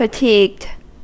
Ääntäminen
Synonyymit tired Ääntäminen US : IPA : [fəˈtiːɡd] Tuntematon aksentti: IPA : /fəˈtiːɡɪd/ Haettu sana löytyi näillä lähdekielillä: englanti Fatigued on sanan fatigue partisiipin perfekti.